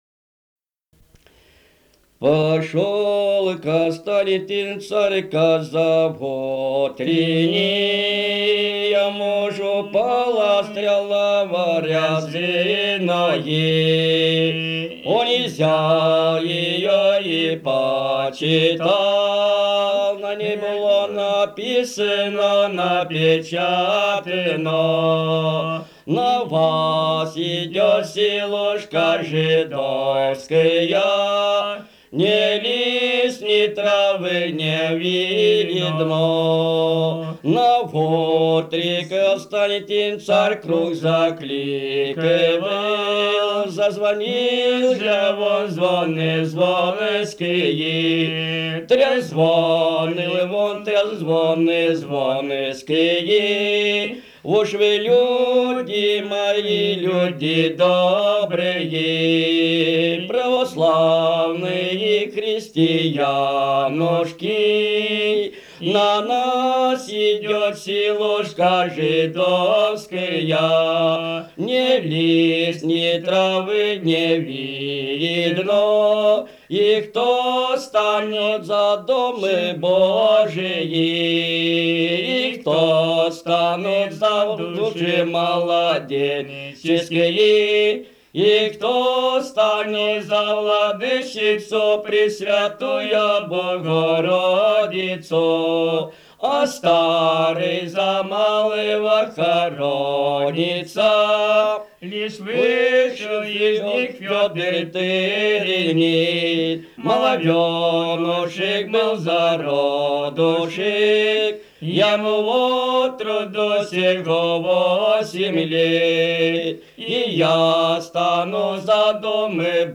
Собрание имеет звуковое приложение – диски с записью аутентичного исполнения былинных песен и духовных стихов самими носителями эпических традиций.